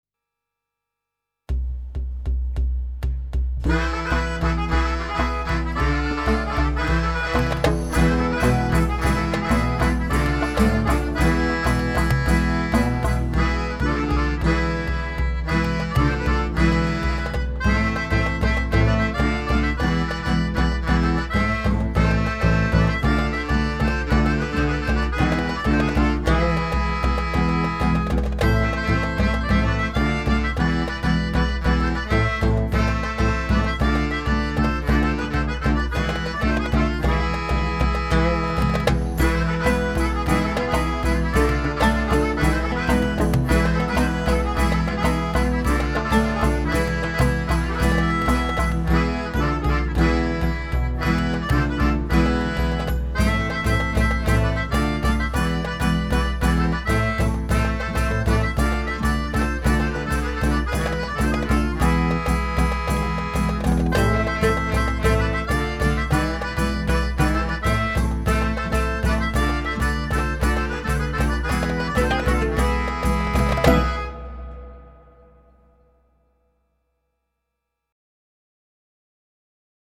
Melodion
Harmonica
and Qchord